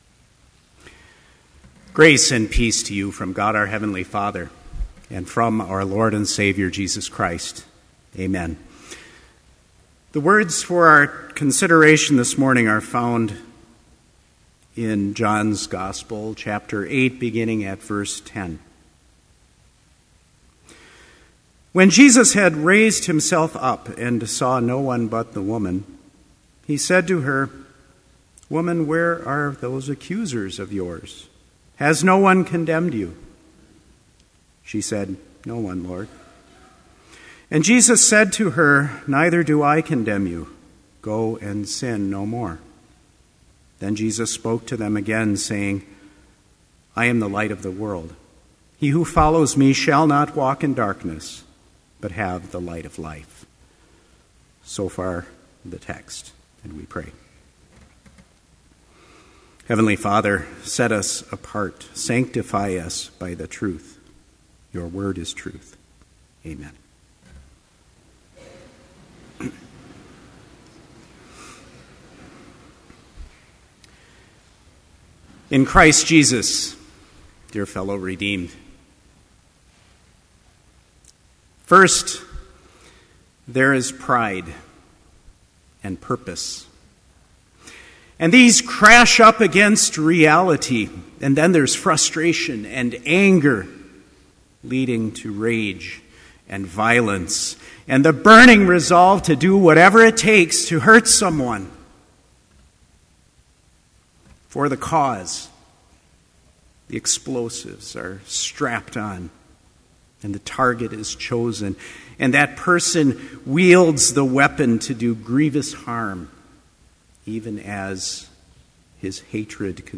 Complete Service
• Hymn 240, Jesus, Refuge of the Weary
• Homily
This Chapel Service was held in Trinity Chapel at Bethany Lutheran College on Tuesday, February 12, 2013, at 10 a.m. Page and hymn numbers are from the Evangelical Lutheran Hymnary.